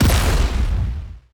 explosion
Explosion0002.ogg